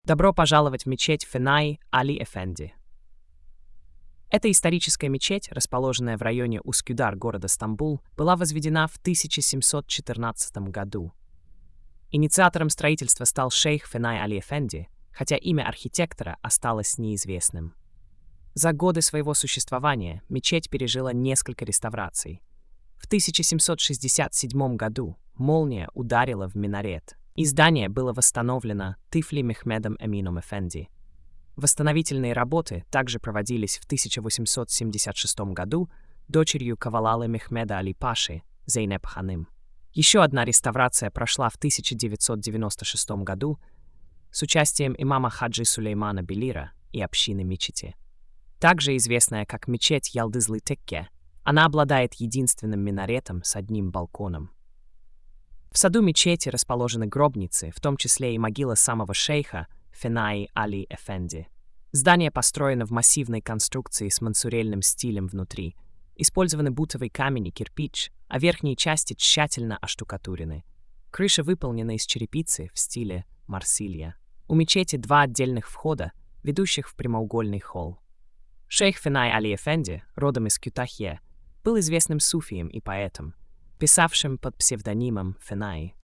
Аудиоповествование